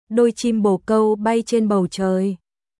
Đôi chim bồ câu bay trên bầu trời.鳩のつがいが空を飛んでいる。ドイ チム ボー カウ バイ チョン バウ チョイ